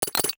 NOTIFICATION_Metal_10_mono.wav